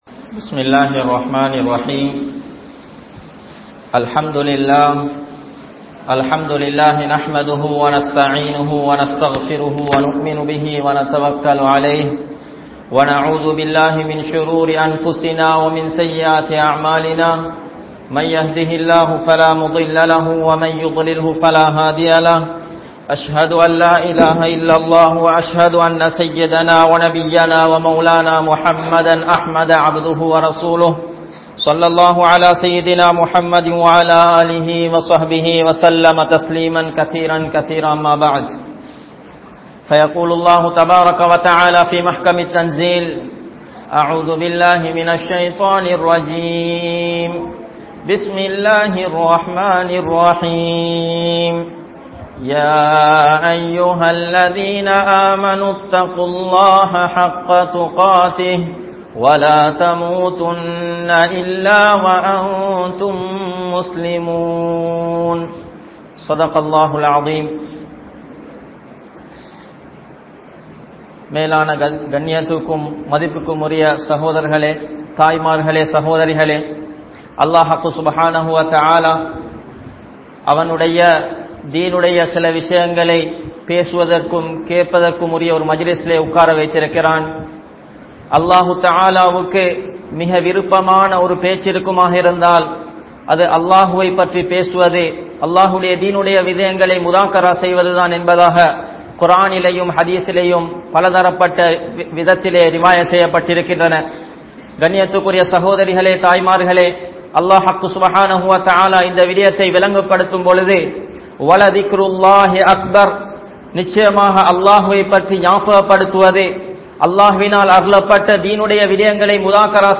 Suvarkaththitkuriya Pengal Yaar?(சுவர்க்கத்திற்குரிய பெண்கள் யார்?) | Audio Bayans | All Ceylon Muslim Youth Community | Addalaichenai